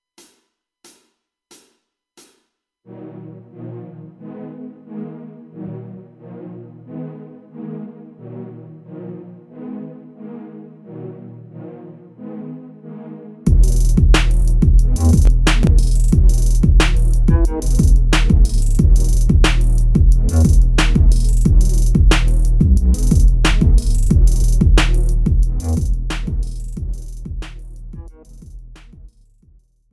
Professional Karaoke Backing Track.
Hip Hop